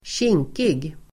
Uttal: [²tj'ing:kig]